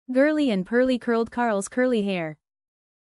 TONGUE TWISTER 早口言葉
/gɚ́:li: ənd pɚ́:li: kɚ́:ld kɑ́ɚlz kɚ́:li: héɚ/
Tongue-Twister-ɚ́.mp3